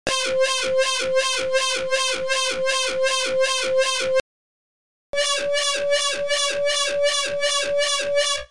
Tiếng chuông Cảnh báo Điện tử Viễn tưởng
Thể loại: Hiệu ứng âm thanh
Description: Tiếng chuông Cảnh báo Điện tử Viễn tưởng là âm thanh cảnh báo vang lên liên hồi là âm thanh viễn tưởng, âm thanh kêu lên liên tục thể hiện cảnh báo nguy hiểm, thông báo cho toàn bộ người có mặt cần xử trí nhanh và di chuyển kịp thời, chuông cảnh báo vang lên liên hồi thường sử dụng trong các bộ phim viễn tưởng, thông báo sự đe dọa nguy hiểm đến tính mạng và con người cần được sơ tán.
Tieng-chuong-canh-bao-dien-tu-vien-tuong-www_tiengdong_com.mp3